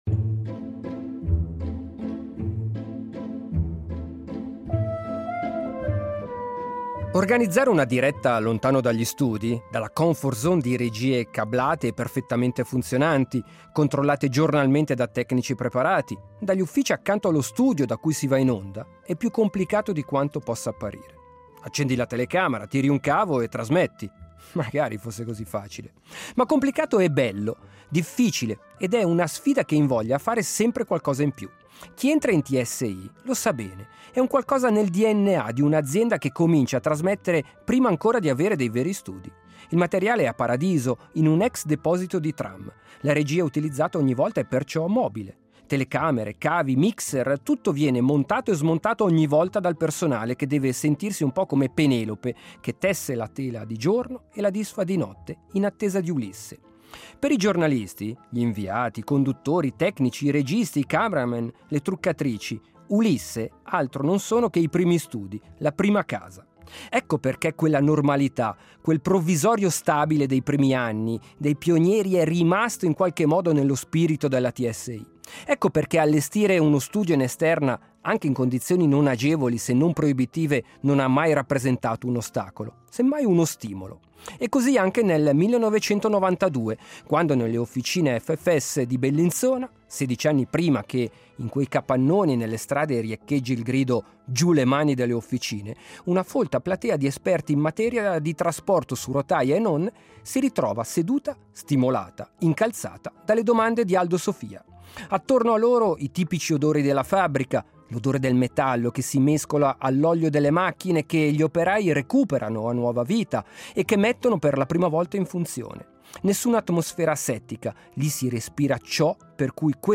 L’ultimo dibattito su Alptransit prima della votazione